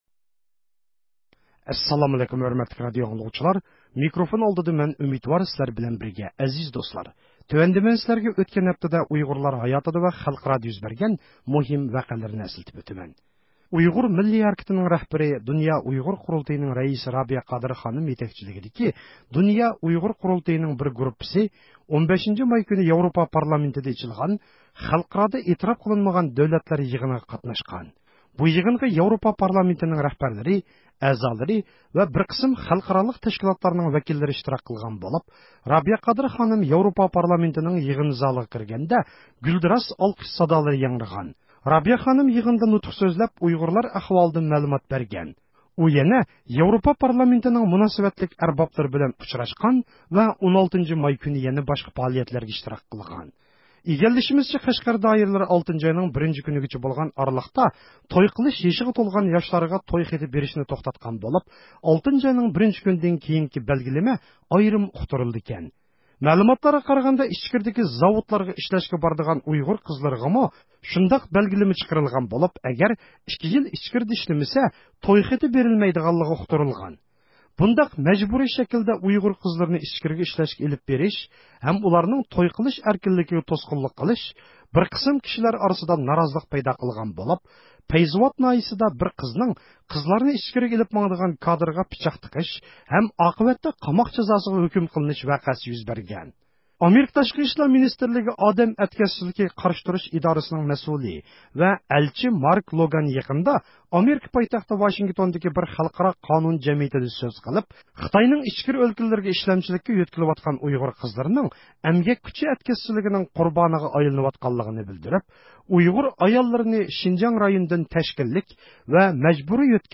ھەپتىلىك خەۋەرلەر (10 – مايدىن 16 – مايغىچە) – ئۇيغۇر مىللى ھەركىتى